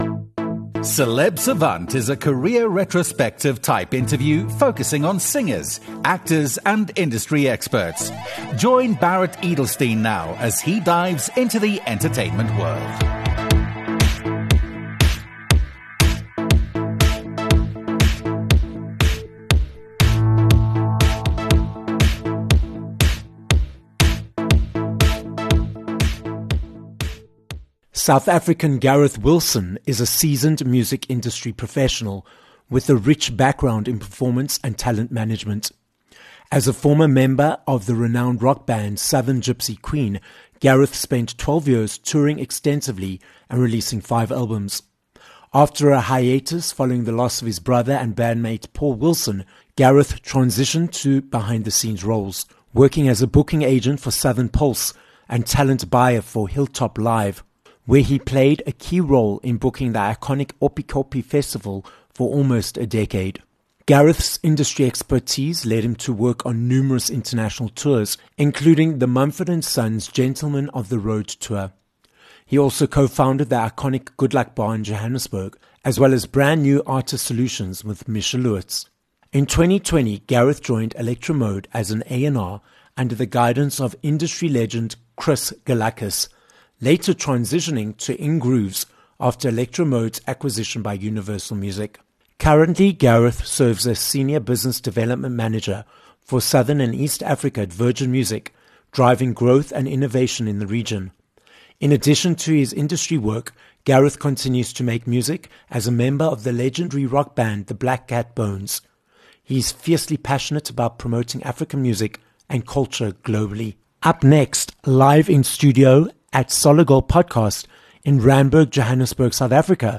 This episode was recorded live in studio at Solid Gold Podcasts, Johannesburg, South Africa Instagram